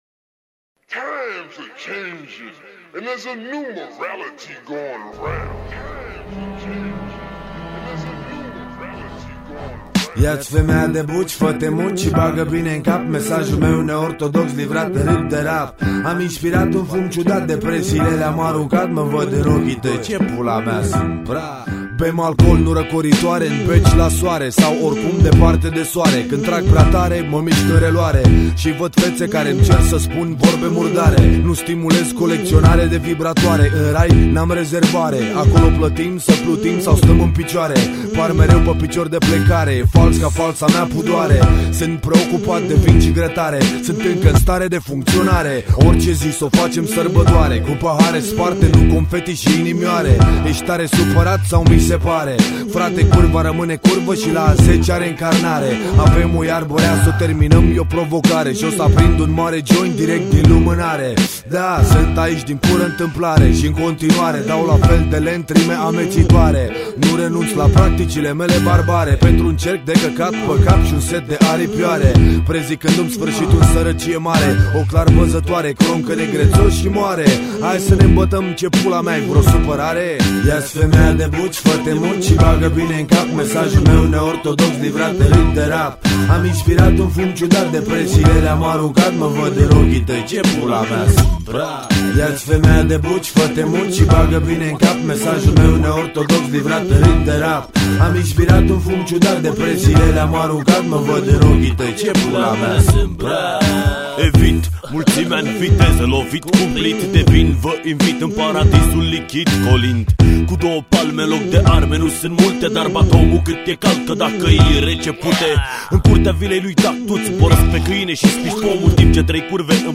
Category: Hip-Hop